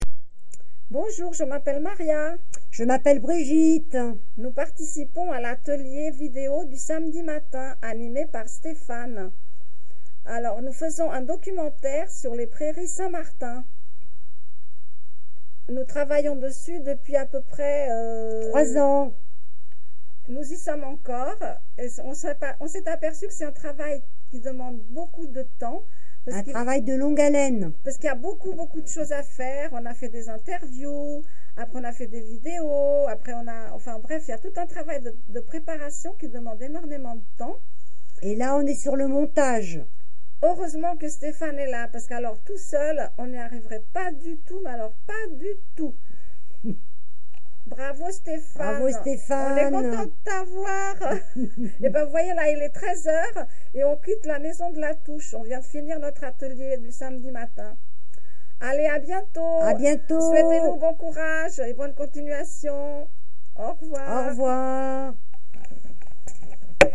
Cabine de témoignages
Témoignage du 4 octobre 2025 à 13h09